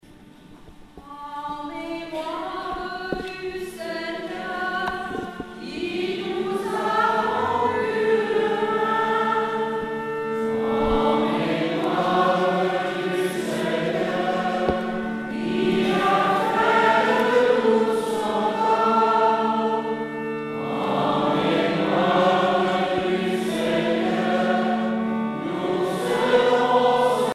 prière, cantique
Genre strophique